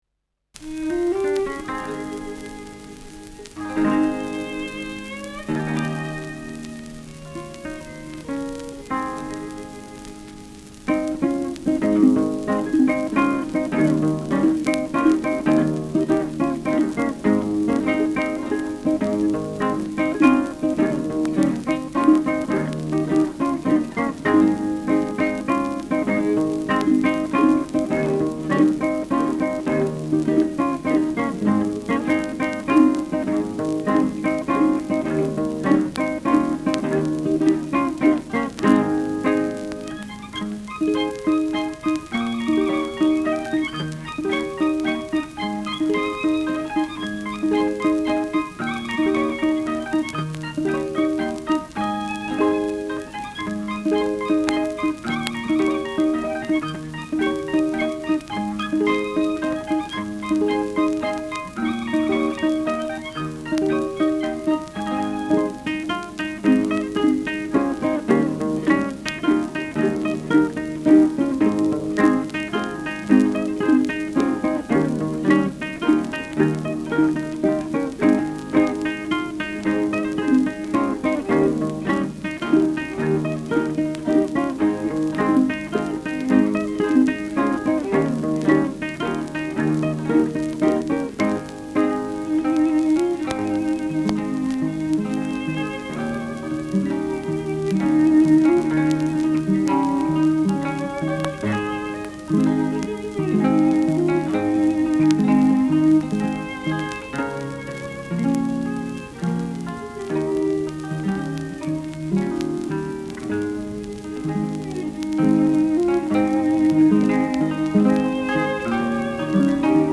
Schellackplatte
leichtes Knistern : leichtes Rauschen : leichtes Knacken bei 0’54’’ : vereinzeltes Knacken
Tegernseer Trio (Interpretation)
[München] (Aufnahmeort)
Stubenmusik* FVS-00016